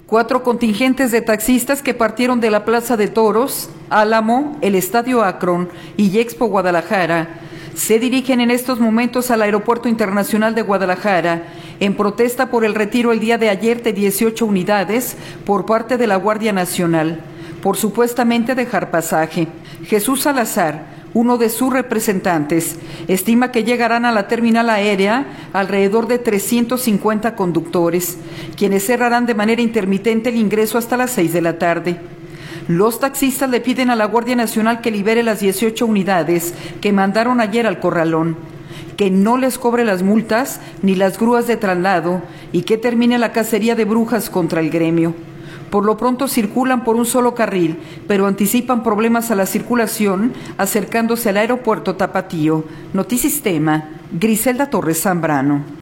TAXISTA.m4a